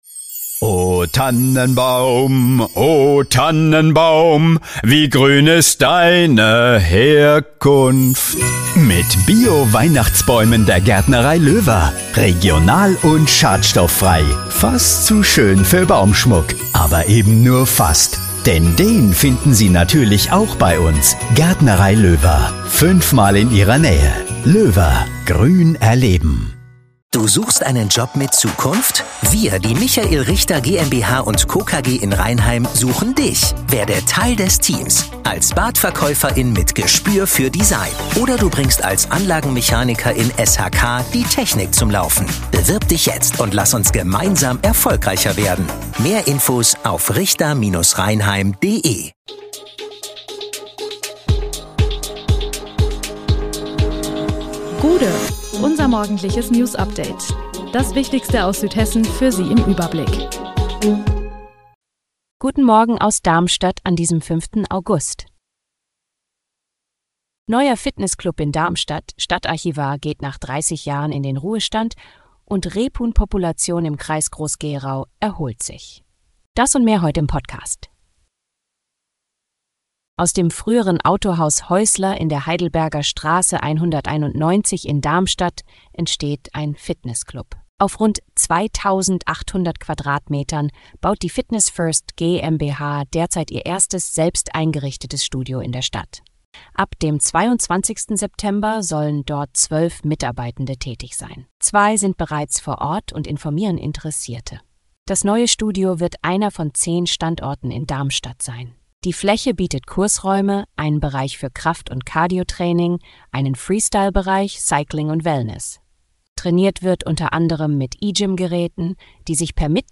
Der Podcast am Morgen für die Region!
Nachrichten